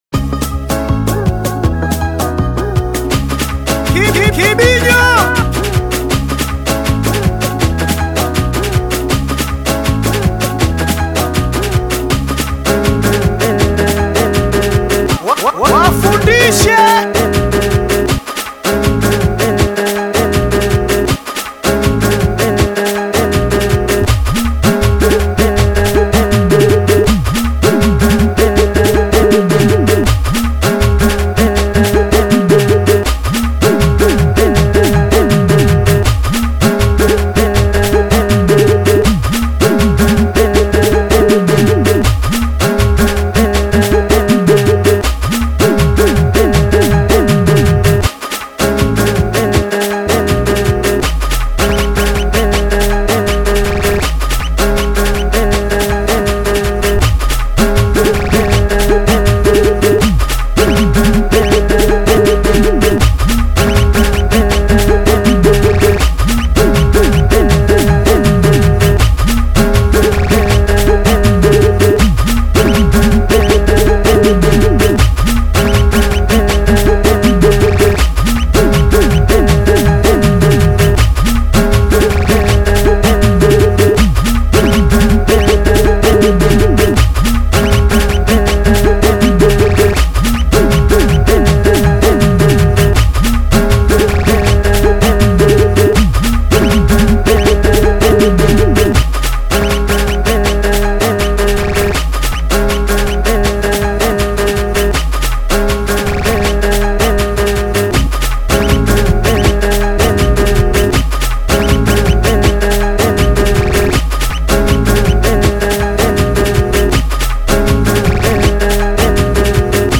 DOWNLOAD BEAT SINGELI